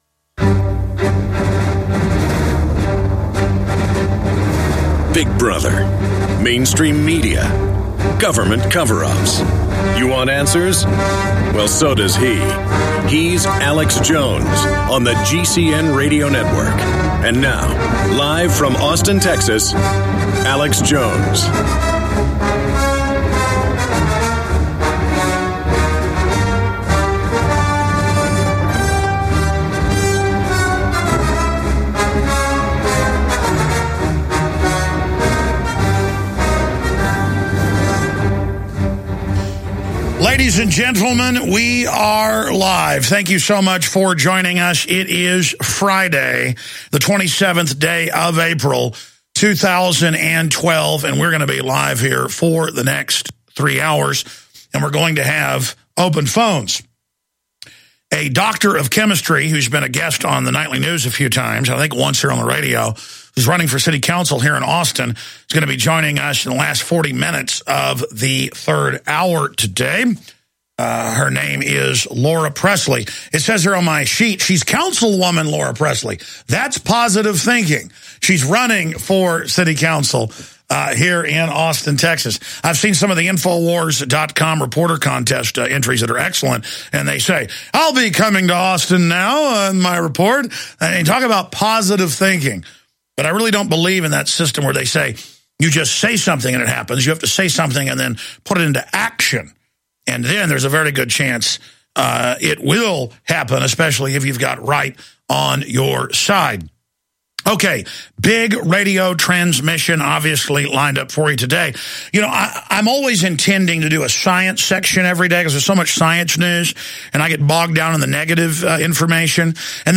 Alex Jones Show Commercial Free Podcast
Watch Alex's live TV/Radio broadcast.